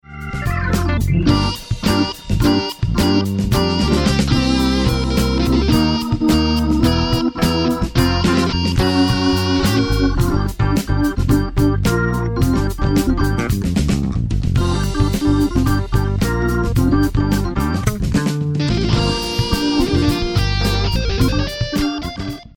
Hammond B3 organ
instrumental